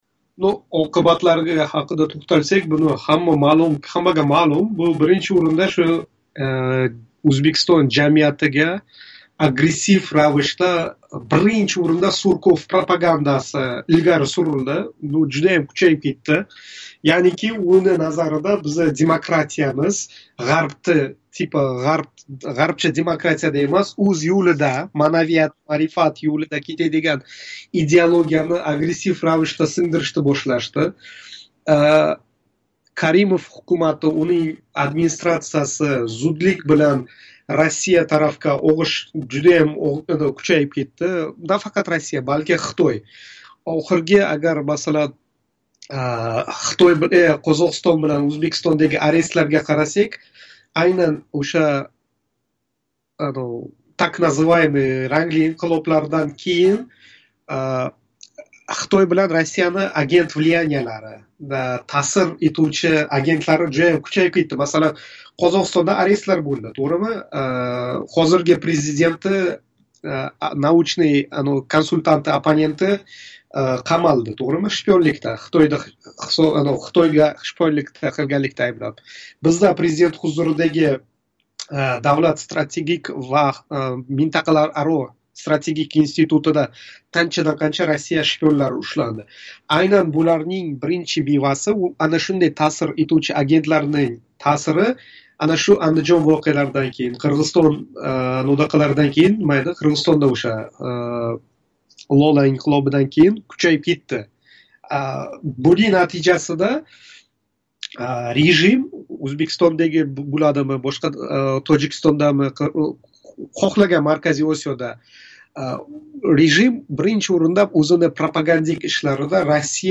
Андижон воқеалари гувоҳи билан интервью